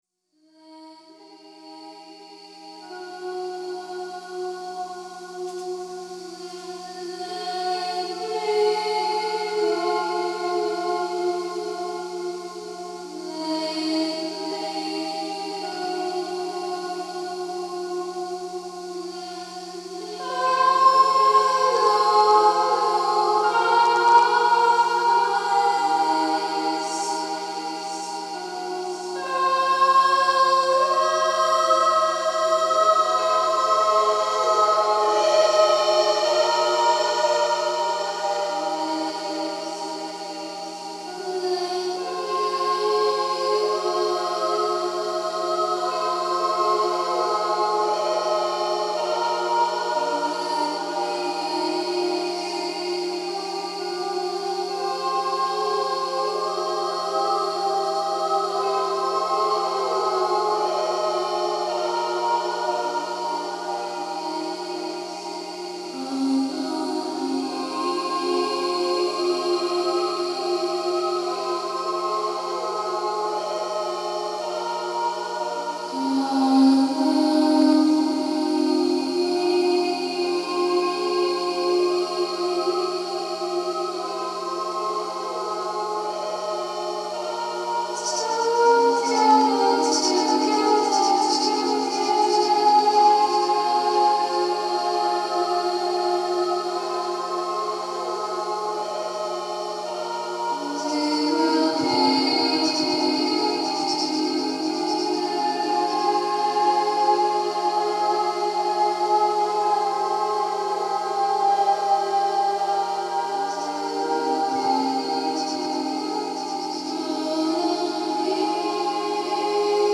est une chanteuse dans un registre new wave et expérimental